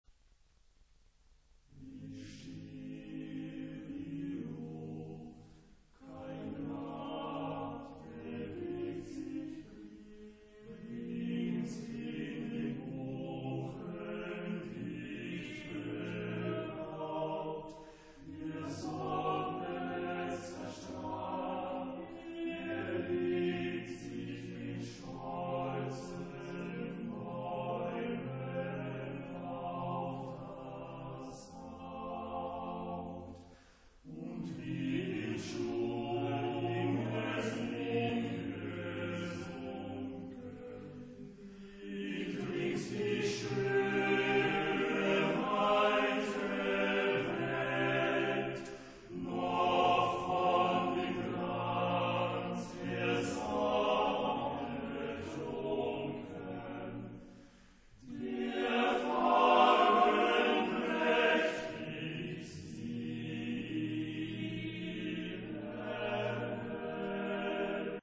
Epoque: 19th century
Genre-Style-Form: Romantic ; Secular
Type of Choir: TTBB  (4 men voices )
Tonality: E major